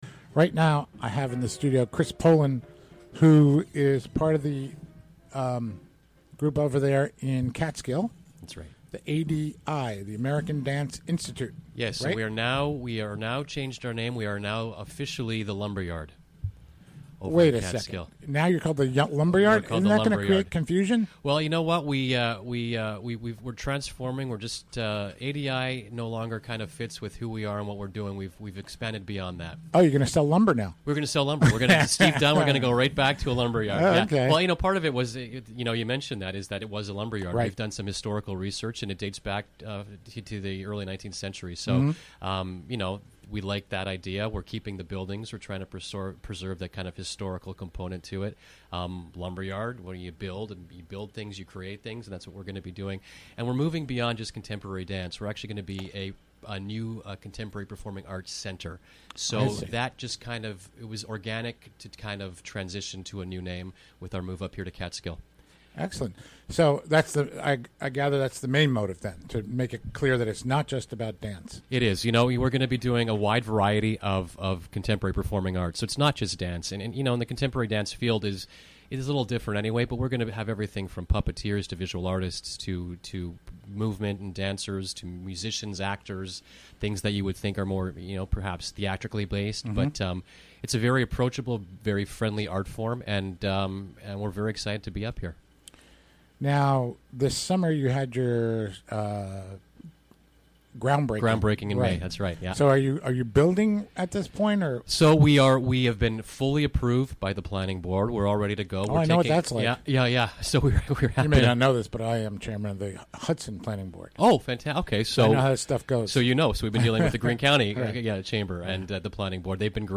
Recorded during the WGXC Afternoon Show Thursday, September 15, 2016.